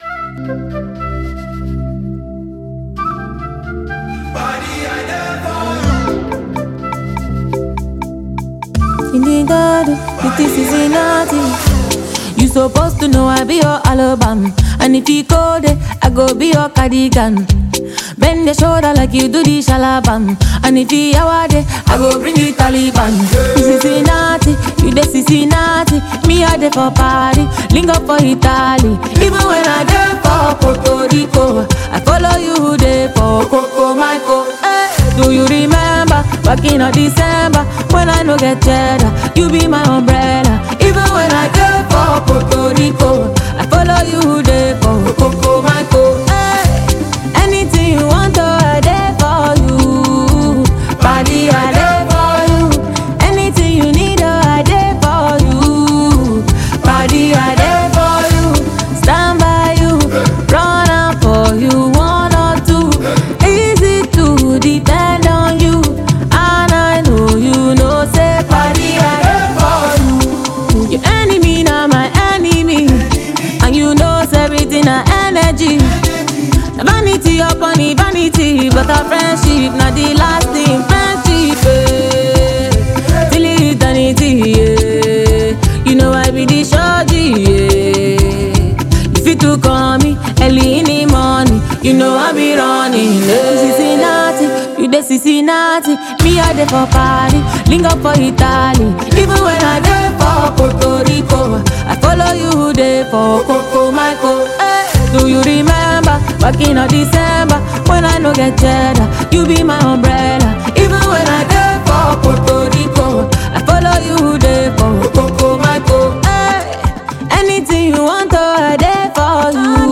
well-produced Naija afrobeats
a super talented Nigerian female artist.